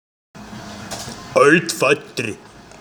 Ahne oder Stammvater Galtür